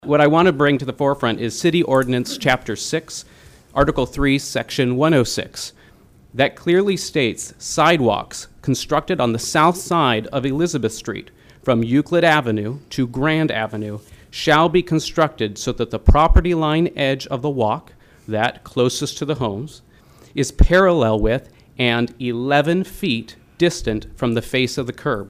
The comments came during the Pierre City Commission meeting’s public comment period.